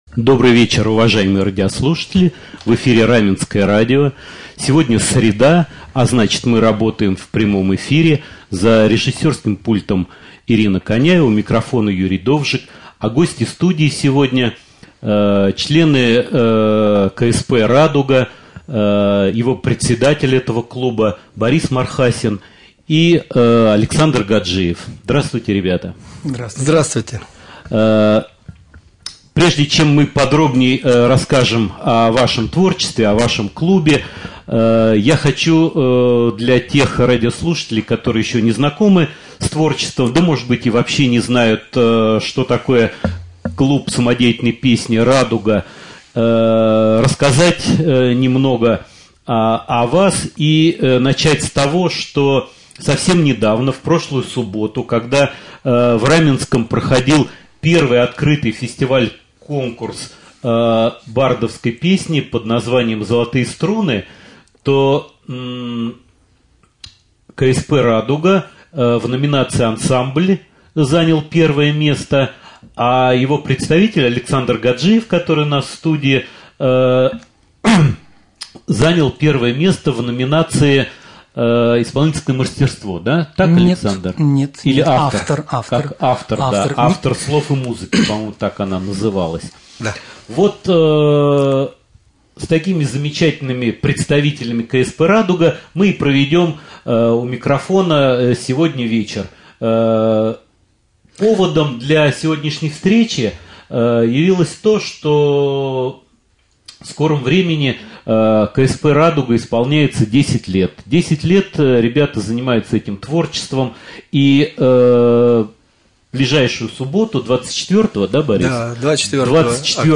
Прямой эфир.
В студии члены клуба самодеятельной песни «Радуга»